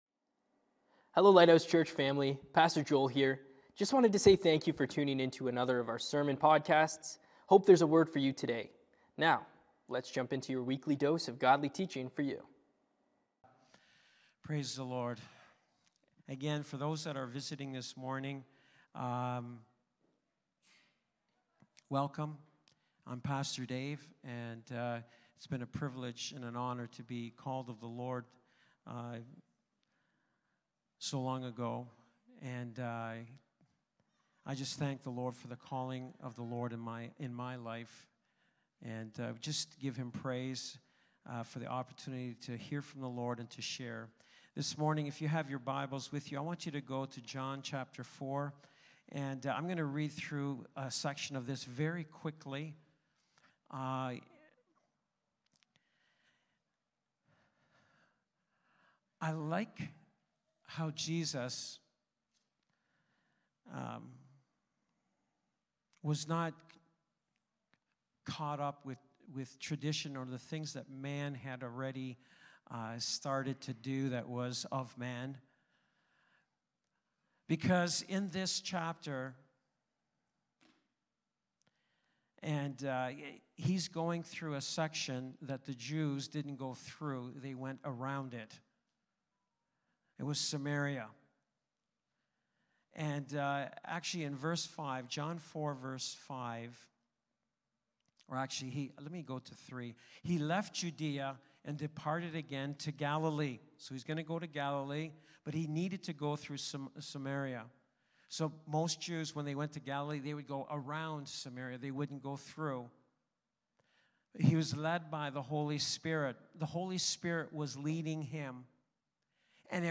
Lighthouse Niagara Sermons